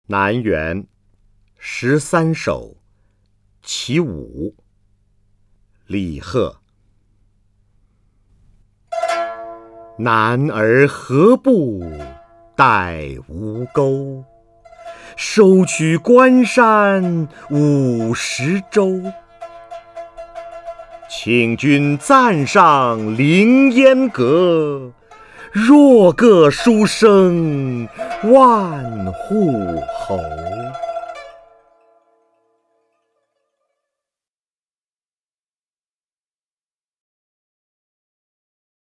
瞿弦和朗诵：《南园十三首·其五》(（唐）李贺) （唐）李贺 名家朗诵欣赏瞿弦和 语文PLUS